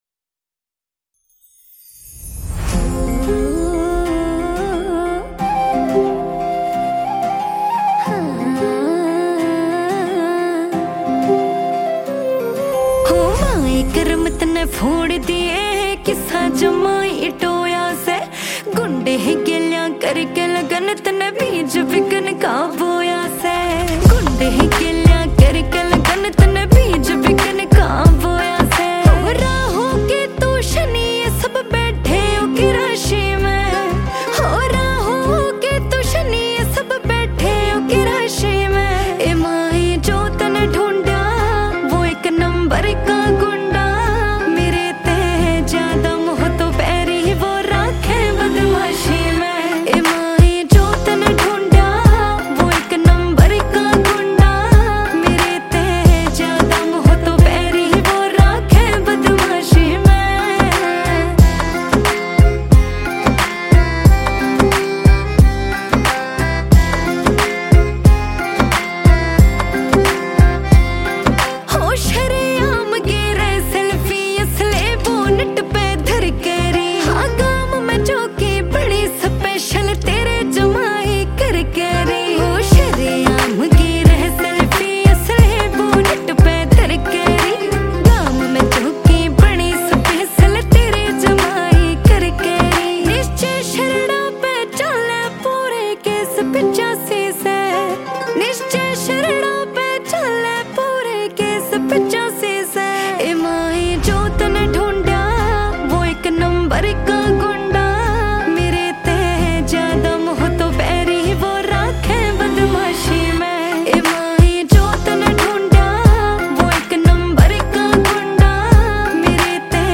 Releted Files Of New Haryanvi Song 2025